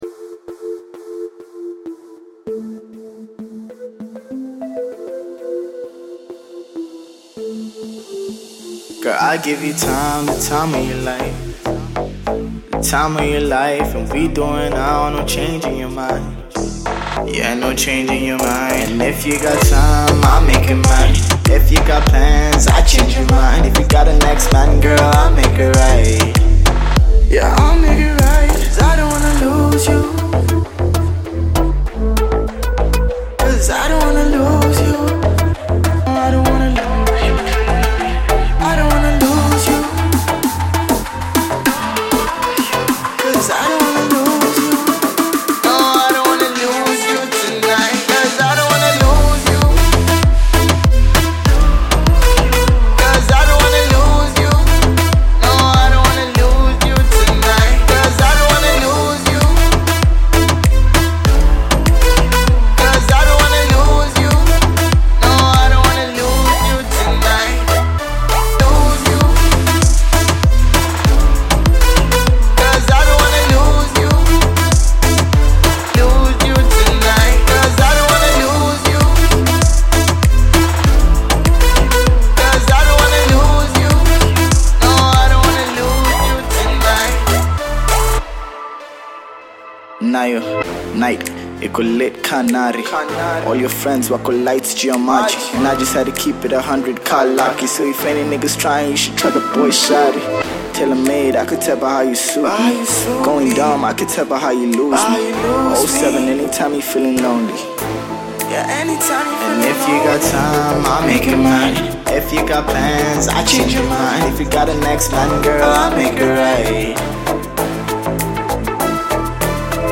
EDM Group